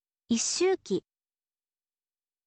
isshuuki